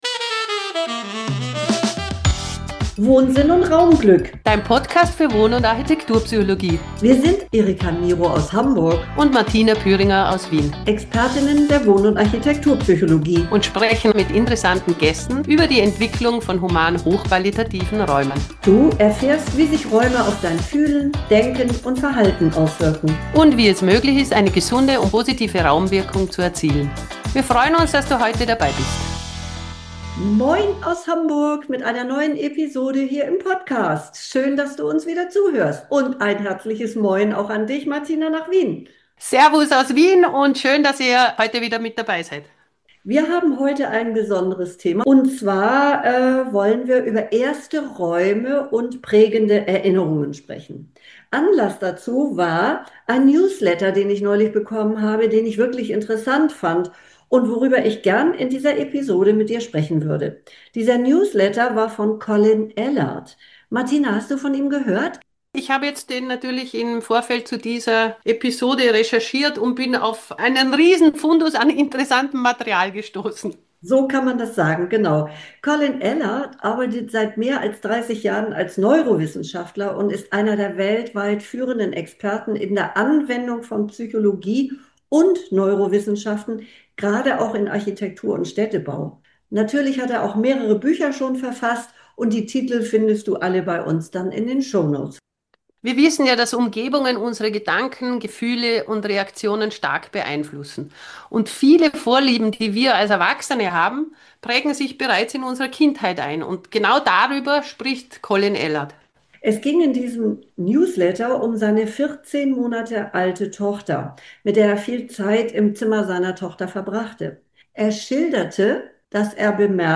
Juhu – wir haben einen neuen, beschwingten Musik-Jingle, der unsere Gespräche eröffnet!